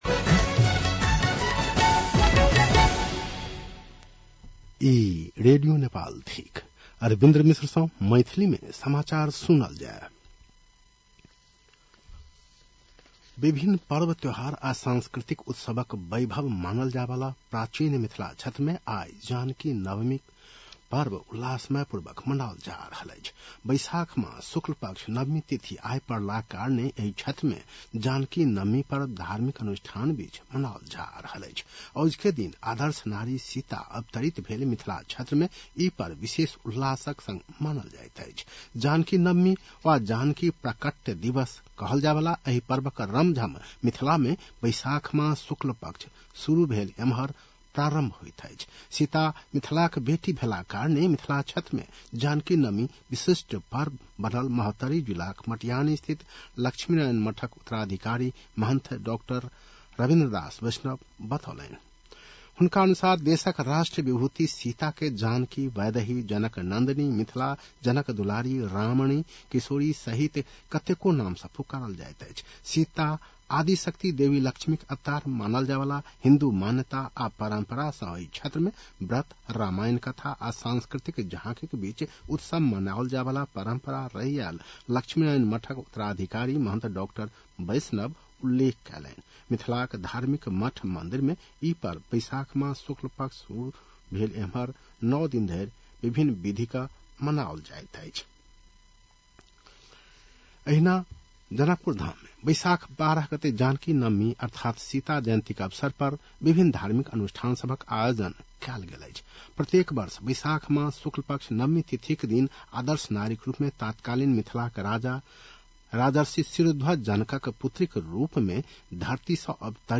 मैथिली भाषामा समाचार : १२ वैशाख , २०८३
6.-pm-maithali-news.mp3